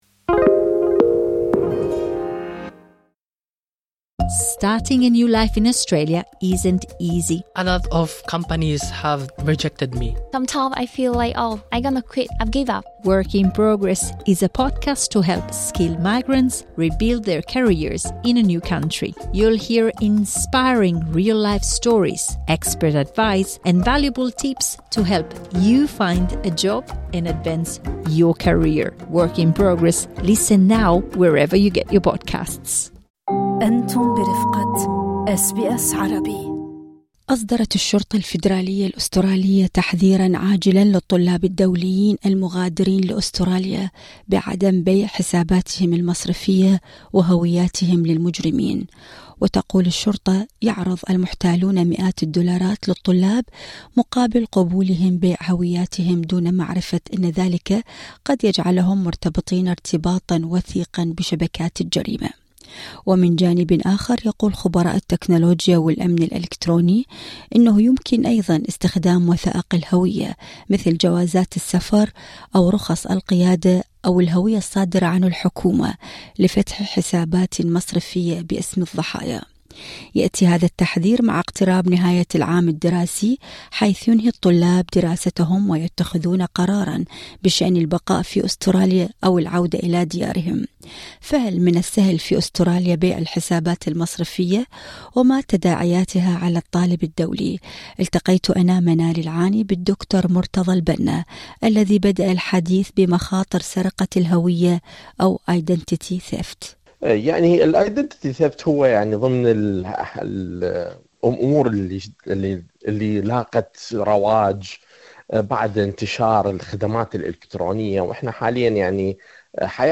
التفاصيل في اللقاء الصوتي اعلاه هل أعجبكم المقال؟